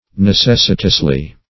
[1913 Webster] -- Ne*ces"si*tous*ly, adv.